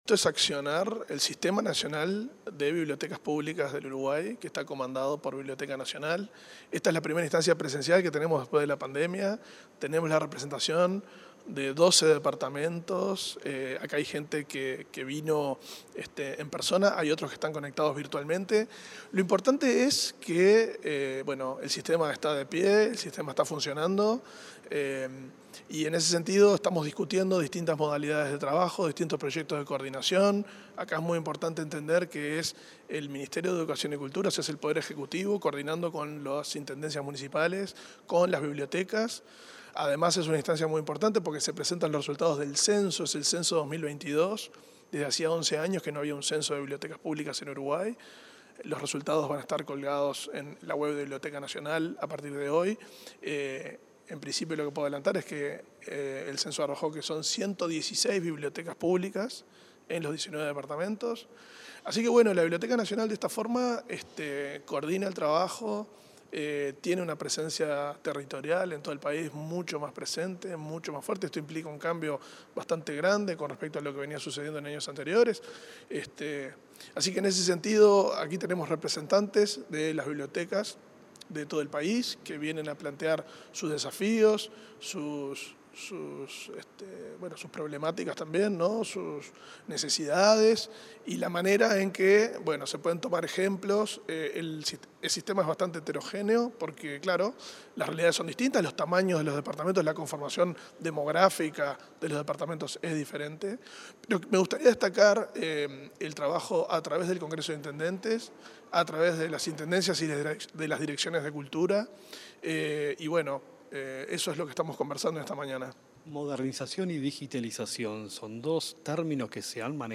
Entrevista al director de la Biblioteca Nacional, Valentín Trujillo